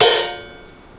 Instrument samples/percussion
Cymbal Staccato